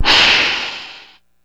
Index of /90_sSampleCDs/E-MU Producer Series Vol. 3 – Hollywood Sound Effects/Water/Alligators
GATOR HISS-L.wav